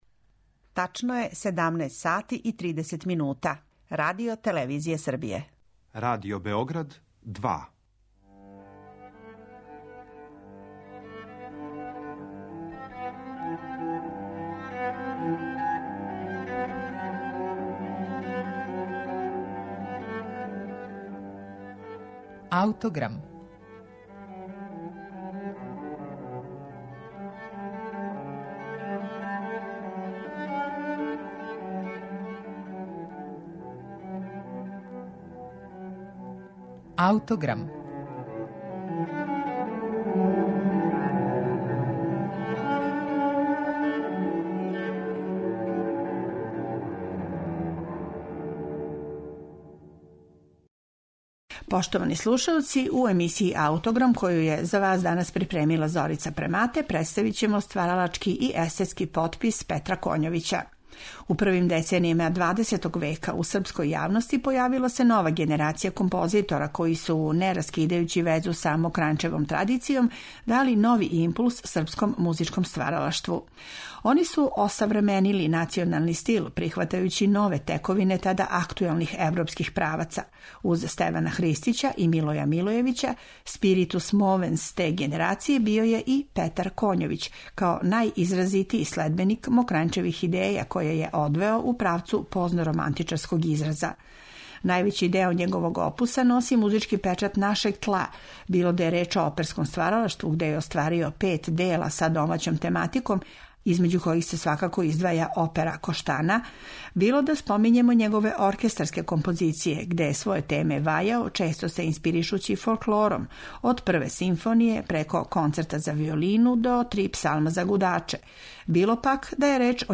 Прва српска симфонија